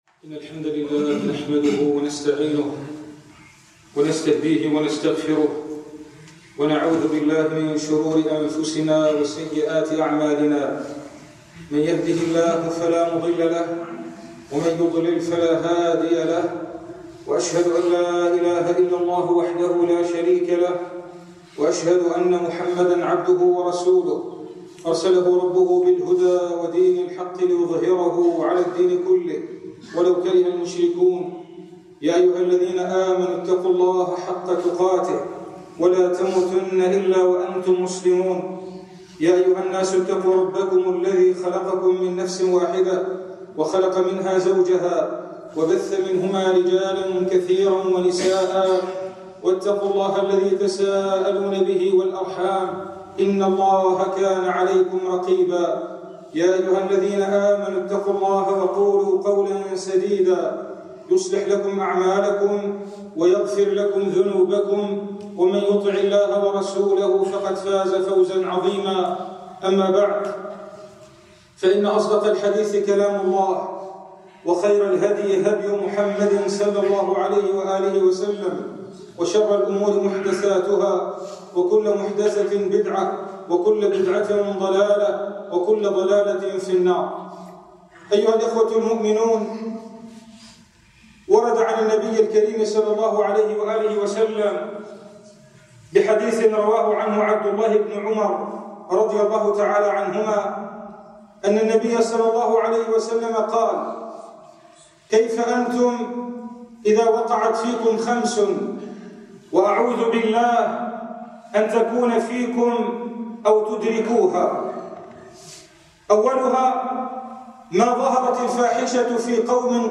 [خُطبة] كَيْفَ بِكُمْ إِذا وَقَعَتْ فِيكُمْ خَمْسٌ وَأَعُوذُ بِاللهِ أَنْ تَكُونَ فِيكُمْ أَوْ تُدْرِكوها